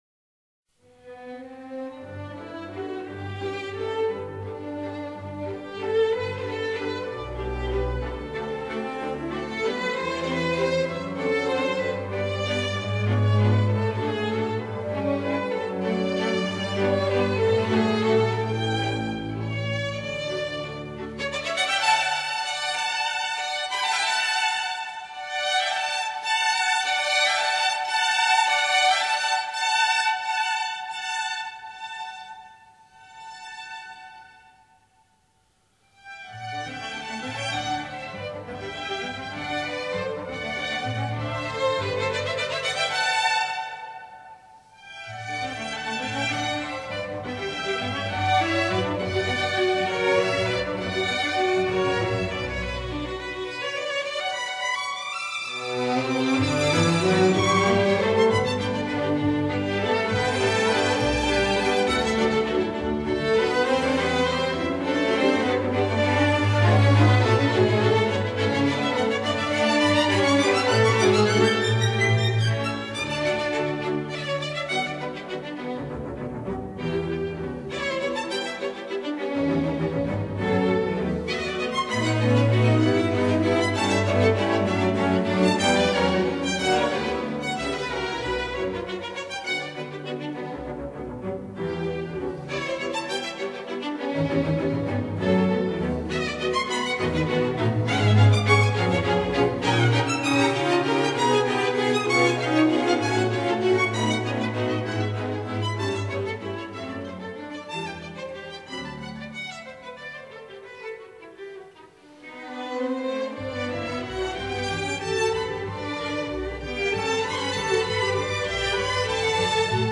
弦乐合奏曲